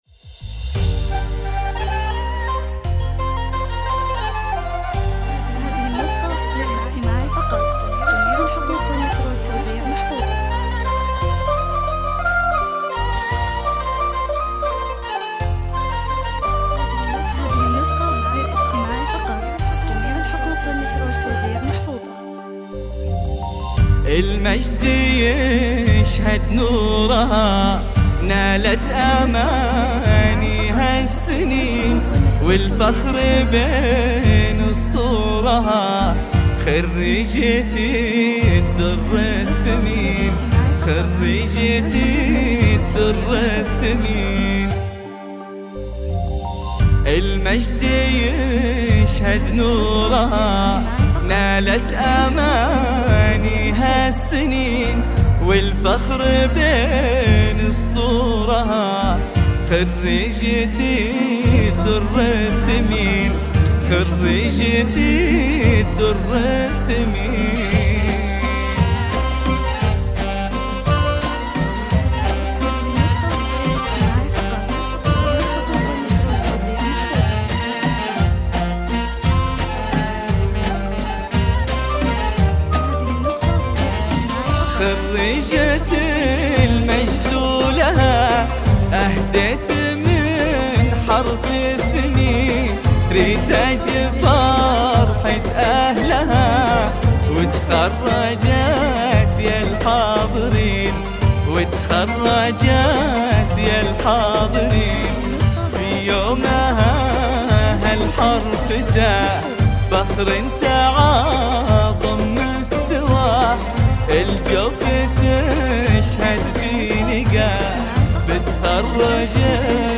زفة اسلامية زفات دفوف للعروس